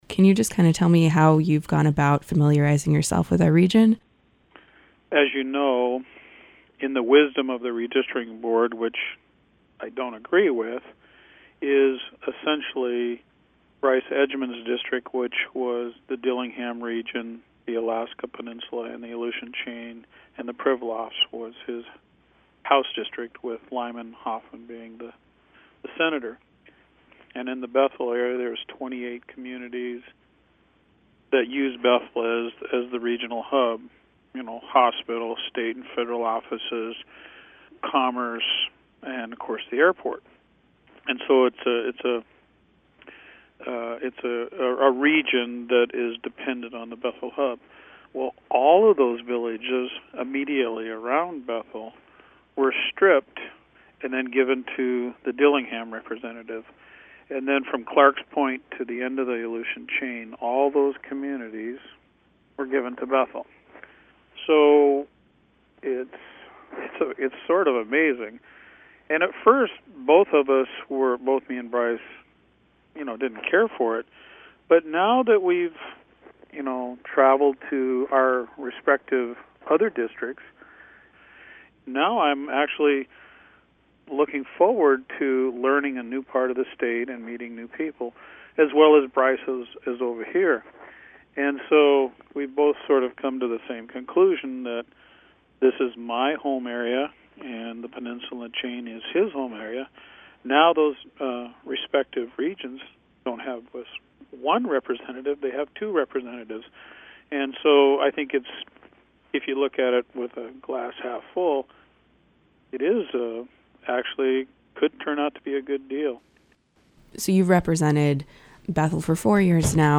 This week, KUCB is airing interviews with candidates for Alaska’s one congressional seat and with the region’s representatives in the State Legislature who are up for reelection.